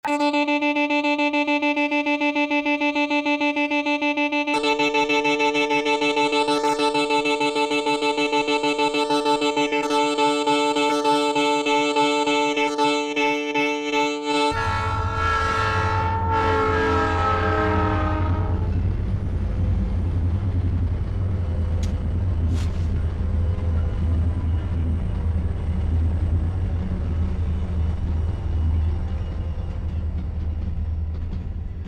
musique concrète